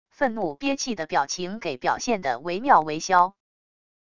愤怒憋气的表情给表现的唯妙唯肖wav音频